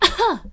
peach_coughing1.ogg